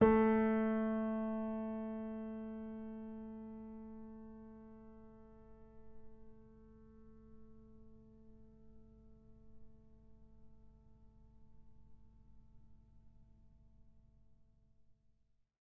sampler example using salamander grand piano
A3.ogg